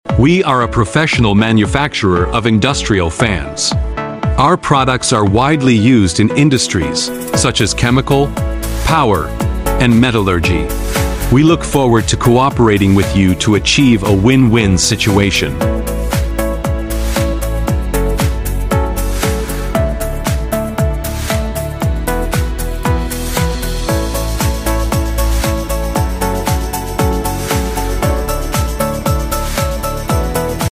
Industrial fan factory#industrialfan#factory#popular#manufacturing#tiktok#foryou#undergroundengineering#tunnelfan#industrialfan